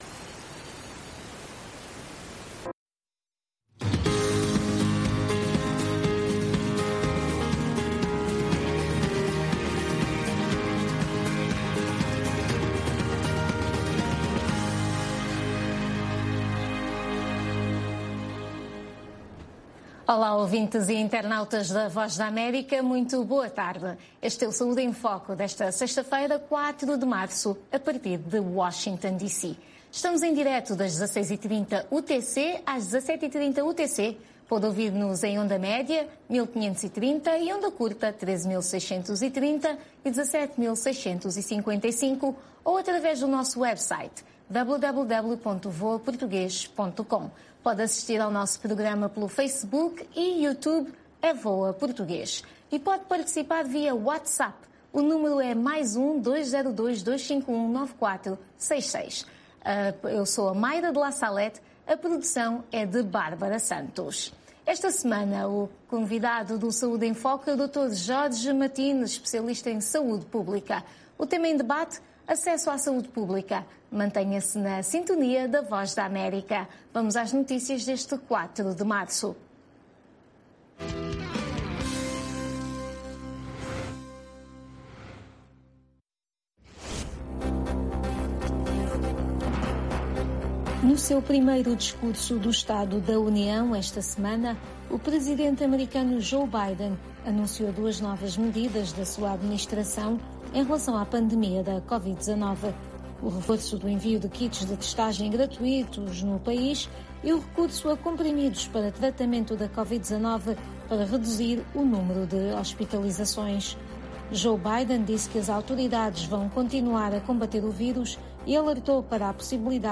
O programa Saúde em Foco é transmitido às sextas-feiras às 16h30 UTC. Todas as semanas falamos sobre saúde, dos Estados Unidos da América para o mundo inteiro, com convidados especiais no campo social e da saúde.